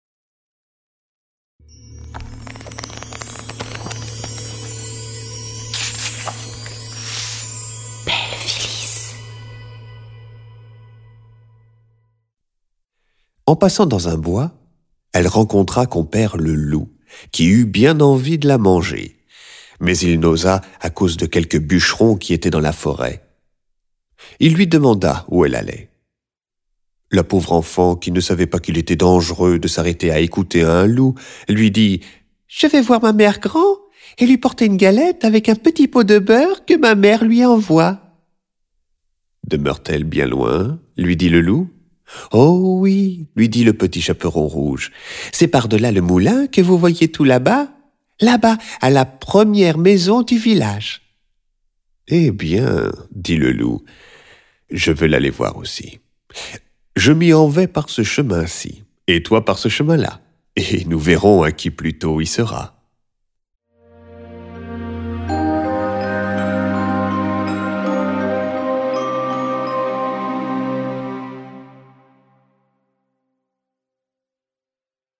je découvre un extrait - Le petit chaperon rouge de Charles Perrault